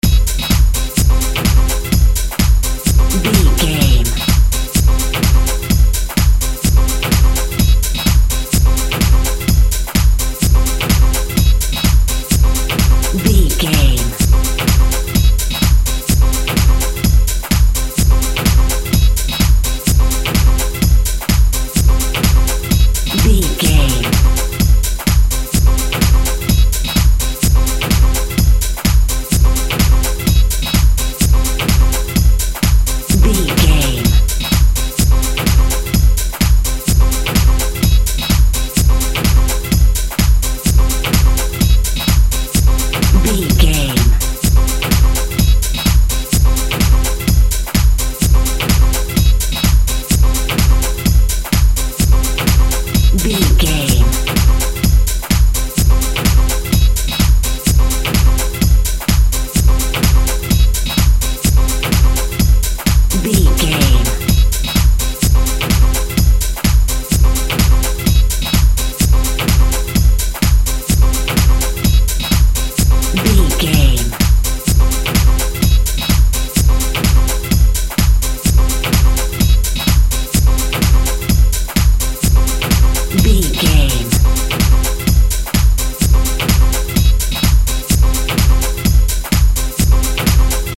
Fast paced
Atonal
intense
futuristic
energetic
driving
repetitive
dark
synthesiser
drum machine
techno
electro house
synth lead
synth bass